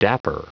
Prononciation du mot dapper en anglais (fichier audio)
Prononciation du mot : dapper